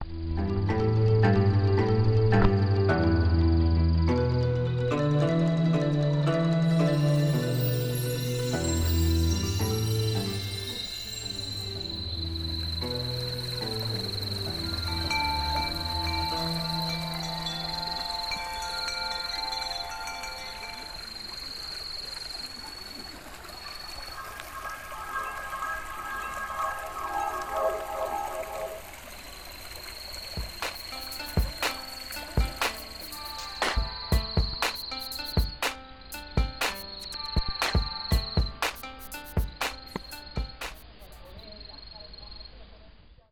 The Japanese koto string melody at the beginning of the soundscape is the same tune as the beat towards the end.
There are gentle synthesized sparkle-like sounds that add an element of abstraction, reflecting the loose brushwork of the painting.